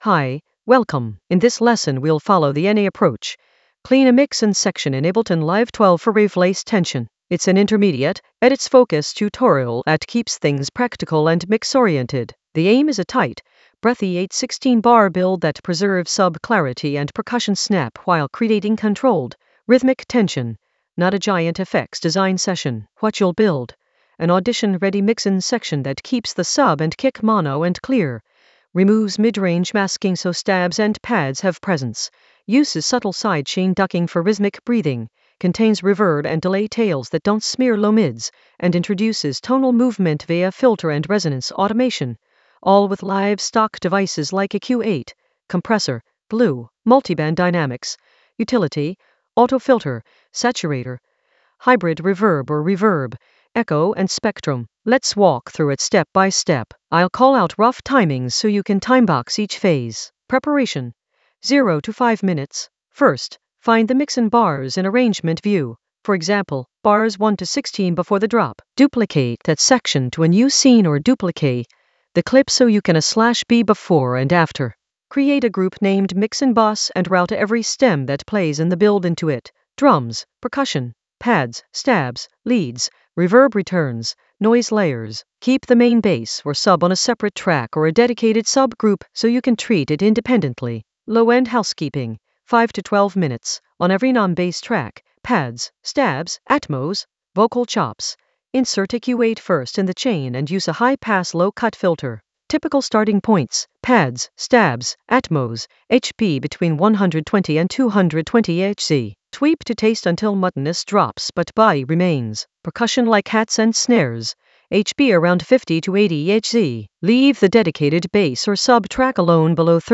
An AI-generated intermediate Ableton lesson focused on Enei approach: clean a mix-in section in Ableton Live 12 for rave-laced tension in the Edits area of drum and bass production.
Narrated lesson audio
The voice track includes the tutorial plus extra teacher commentary.